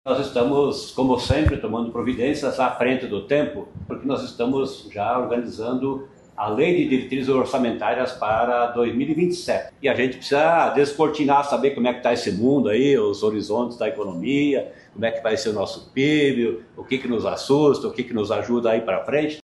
Sonora do secretário da Fazenda, Norberto Ortigara, sobre os estudos do impacto do Paraná Competitivo no PIB